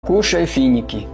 kushai finiki Meme Sound Effect